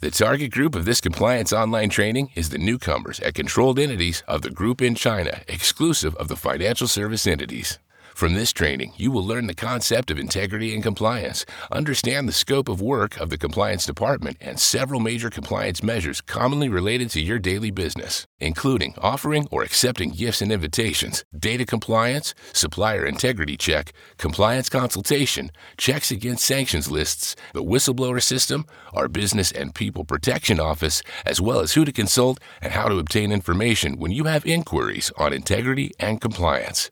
Natural, Llamativo, Accesible, Versátil, Amable
Corporativo